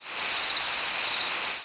Short rising seeps
Ovenbird